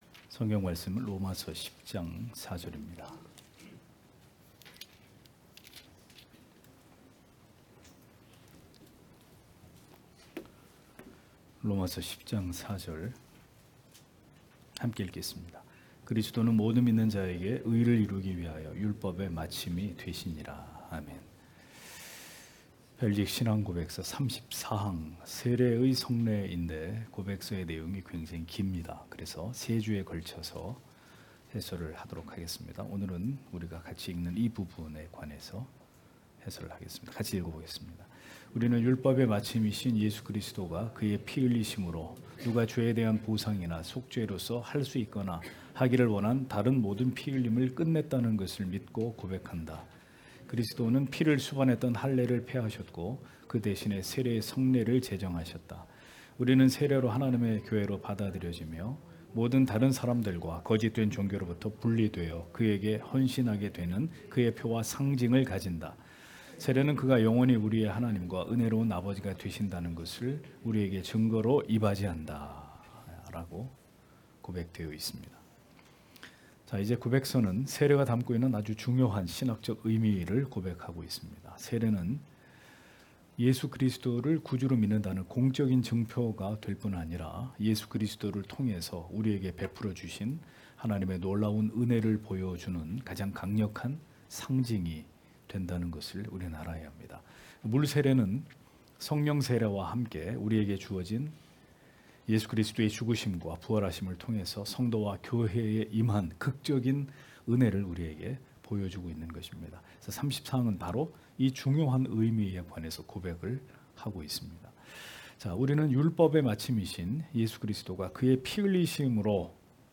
* 설교 파일을 다운 받으시려면 아래 설교 제목을 클릭해서 다운 받으시면 됩니다.